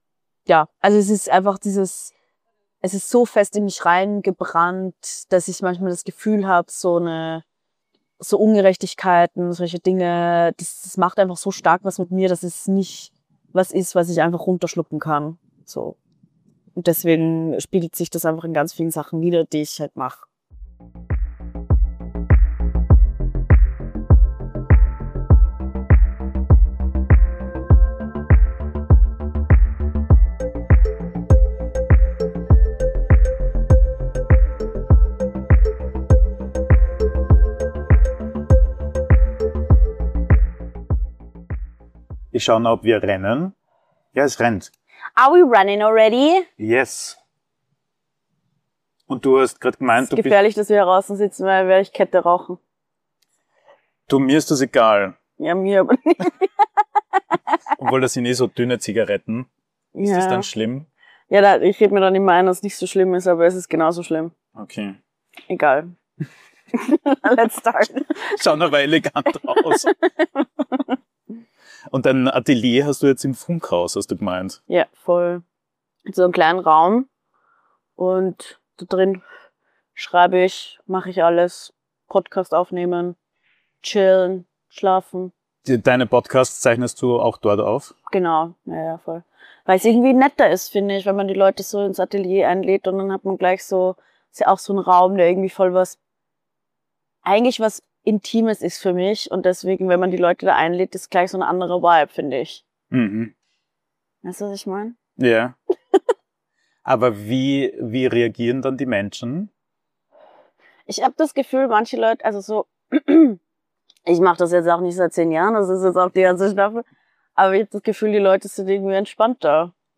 Ein Gespräch über Sprache, Sound, Bilder und darüber, was es heute heißt, Kunst zu leben.